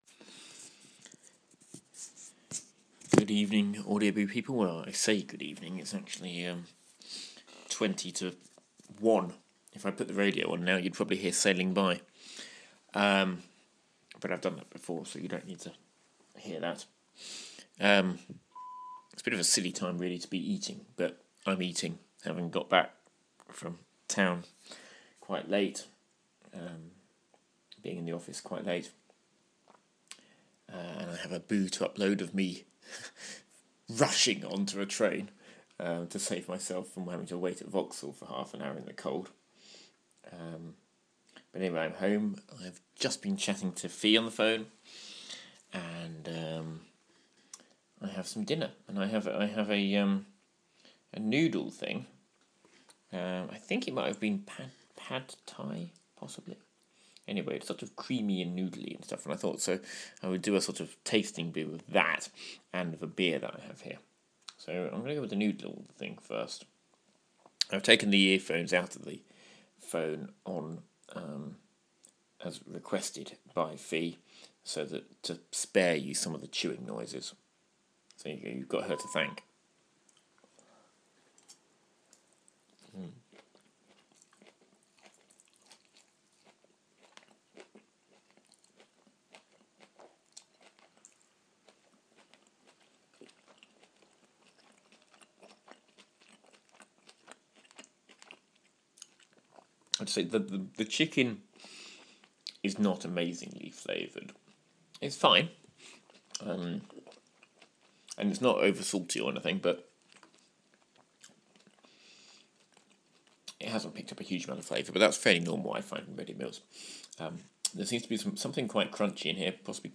Tasting the classic combination of beer and noodles - in the middle of the night. Warning: This boo was made in an environment where food is chewed, and may therefore contain the sound of mastication.